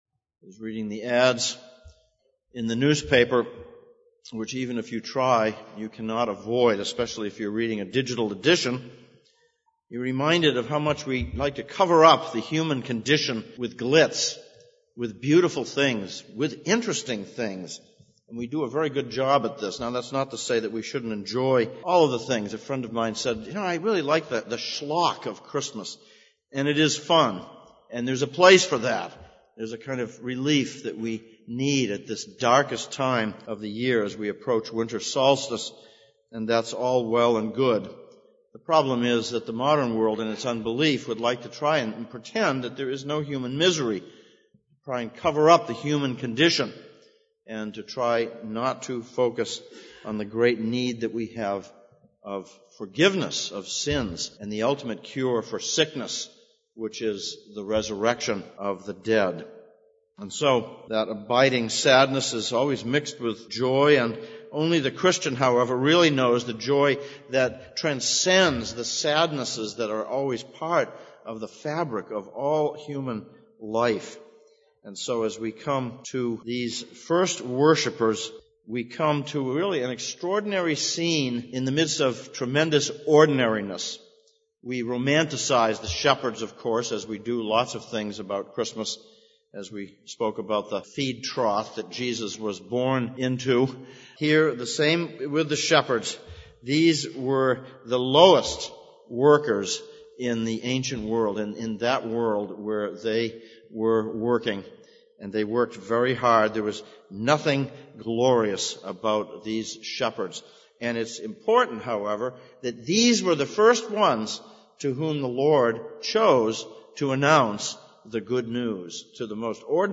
Luke 2:1-14 Service Type: Sunday Morning Sermon verse